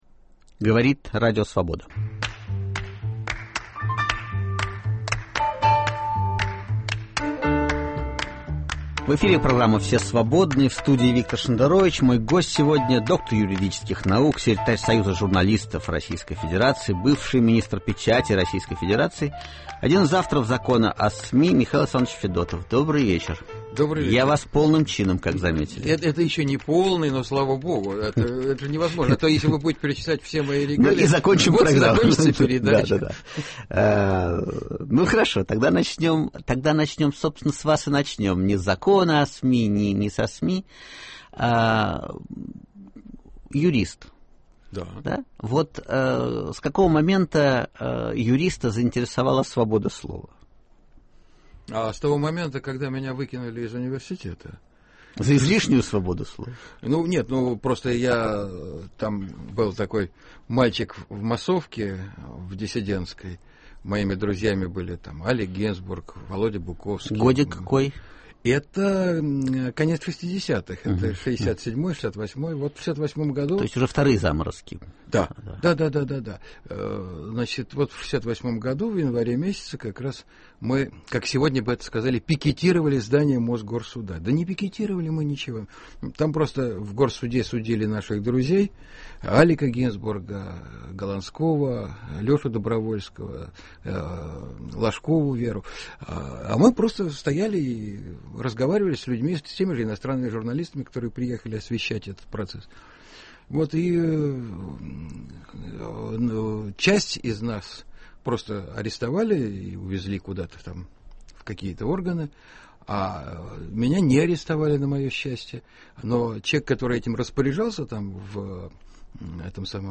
Свобода слова: дозировка и правила применения? В гостях у Виктора Шендеровича – доктор юридических наук, секретарь Союза журналистов РФ, бывший министр печати РФ, один из авторов закона о СМИ Михаил Федотов.